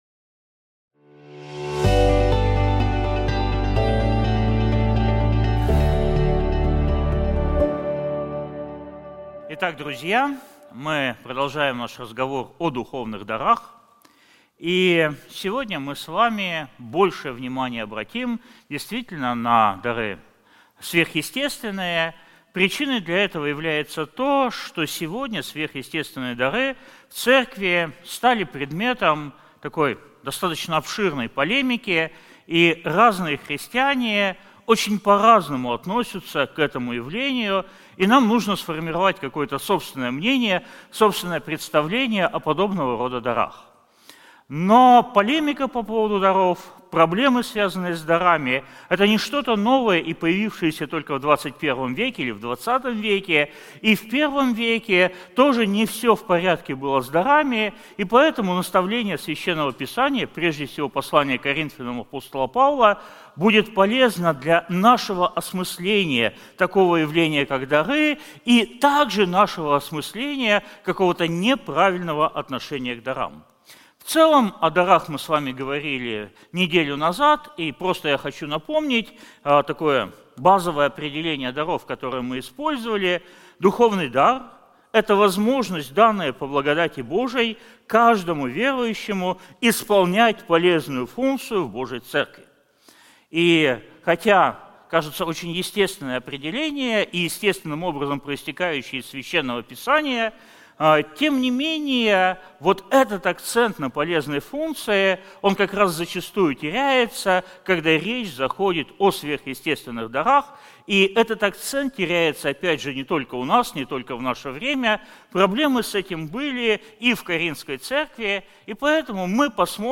Проповеди